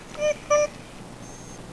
I make this soft whimpering sound. It only lasts a few seconds.
HEAR ME WIMPER (amplified)
wimper.wav